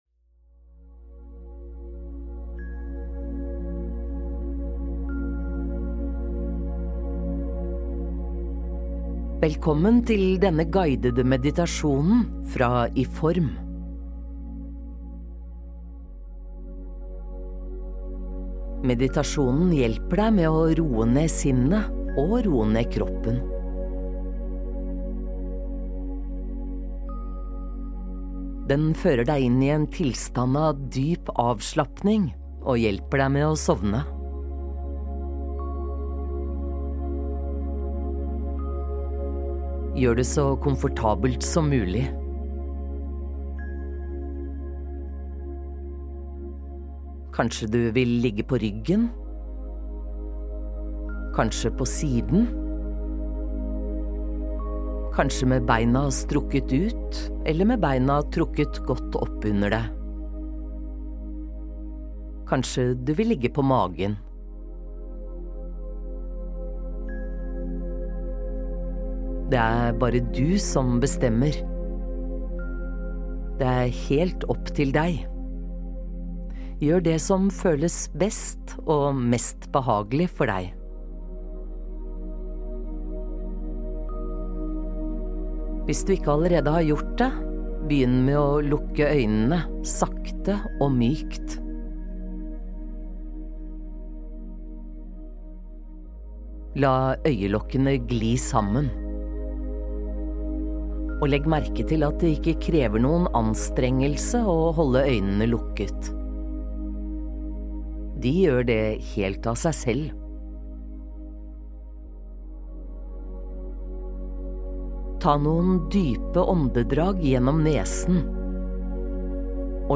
Du blir guidet hele veien - alt du trenger å gjøre er å lene deg tilbake og lytte.
De siste fem minuttene inneholder ingen fortellerstemme, men er bare en fortsettelse av det rolige lydsporet.